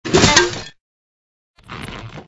AA_drop_flowerpot.ogg